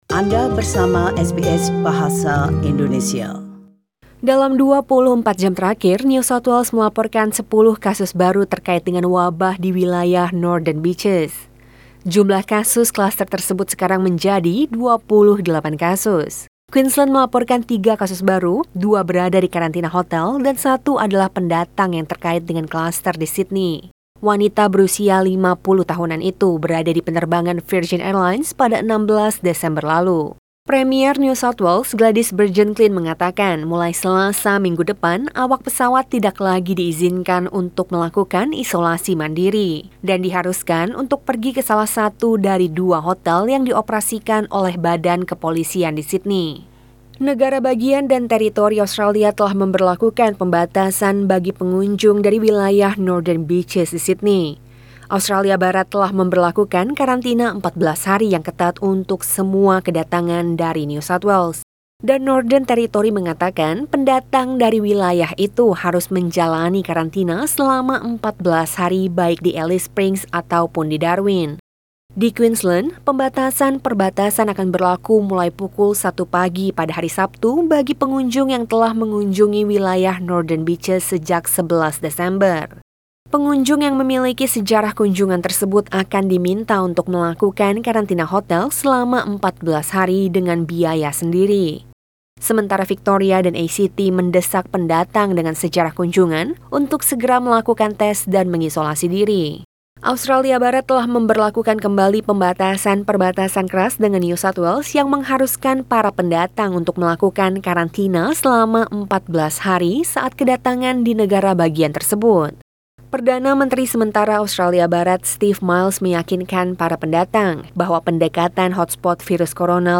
SBS Radio News in Bahasa Indonesia - 18 December 2020
Warta Berita SBS Radio dalam Bahasa Indonesia Source: SBS